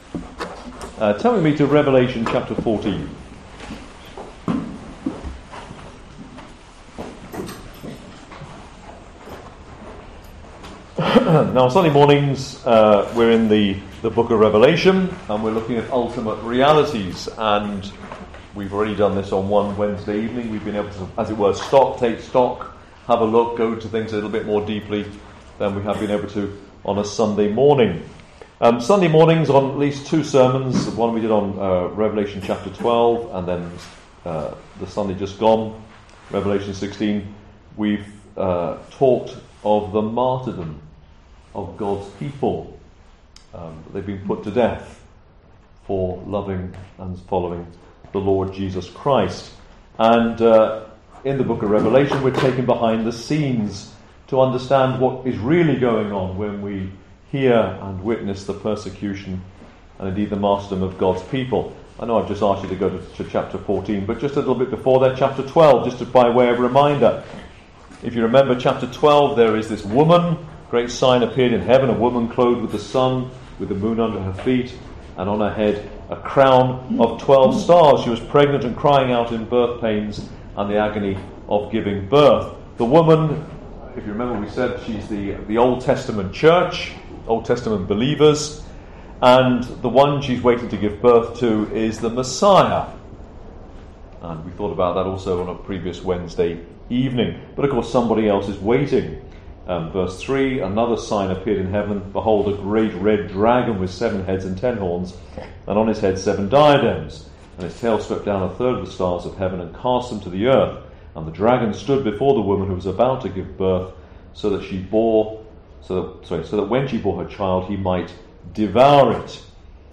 Studies
Wednesday Bible Studies & Prayer Meetings